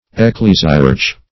Search Result for " ecclesiarch" : The Collaborative International Dictionary of English v.0.48: Ecclesiarch \Ec*cle"si*arch\, n. [LL. ecclesiarcha, fr. Gr.
ecclesiarch.mp3